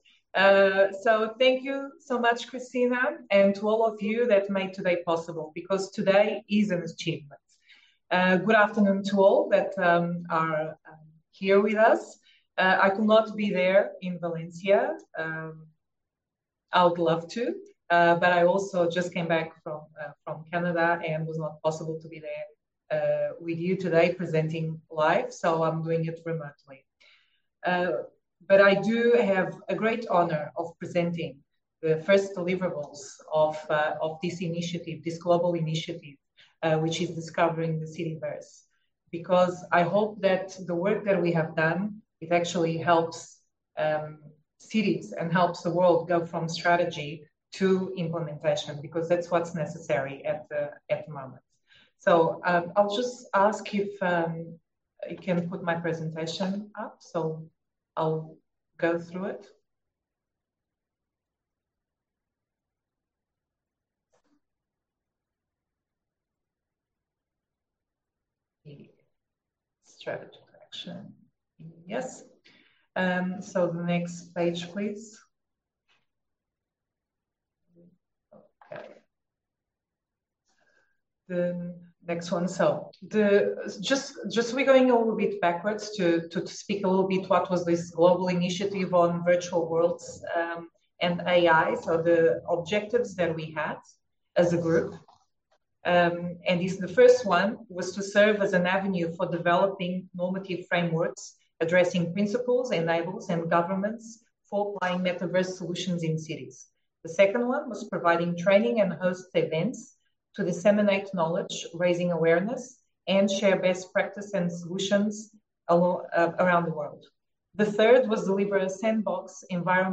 Tratou-se de um encontro realizado a partir da cidade de Valência, em Espanha, sob a égide da Organização das Nações Unidas (ONU), no qual Andreia Collard apresentou, por videoconferência, os primeiros resultados da iniciativa que pretende construir infraestruturas públicas digitais, através dos mundos virtuais baseados em inteligência artificial.